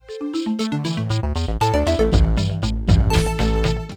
Knackser in der Aufnahme
entklickt-wav.27377